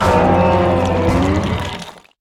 Cri de Dinglu dans Pokémon HOME.